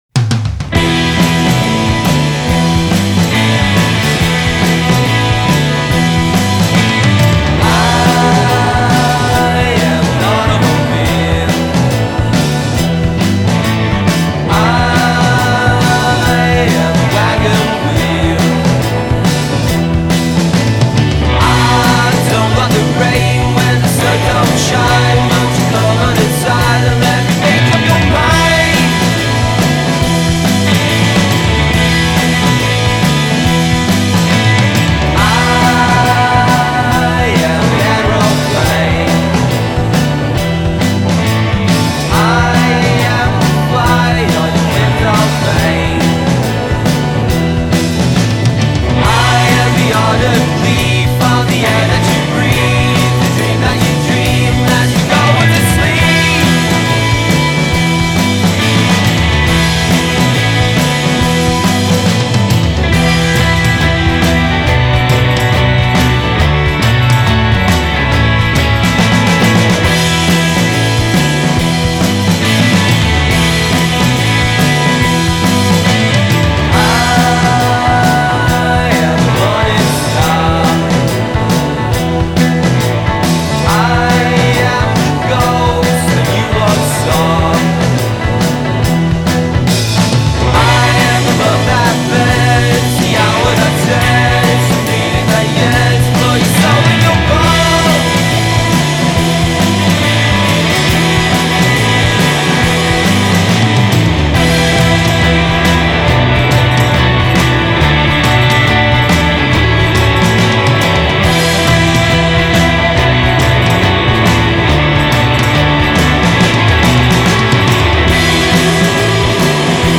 Genre: Indie Pop-Rock / Psychedelic Rock